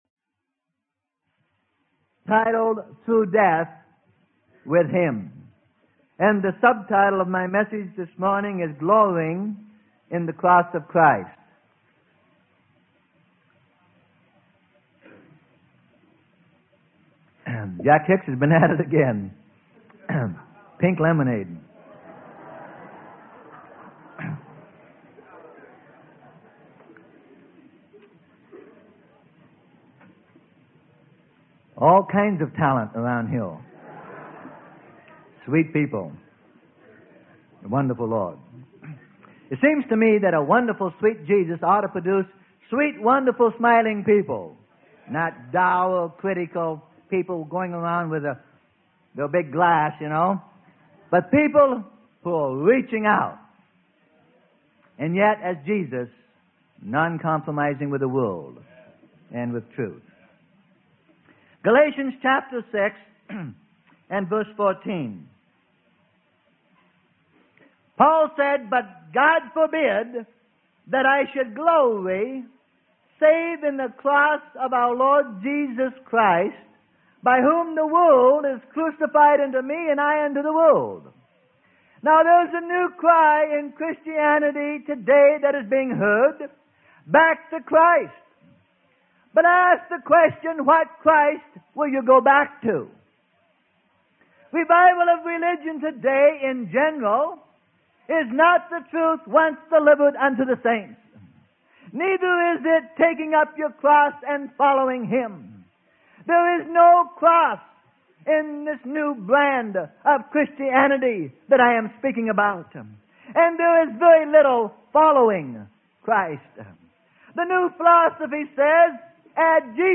Sermon: Through Death with Him - Part 07 - Freely Given Online Library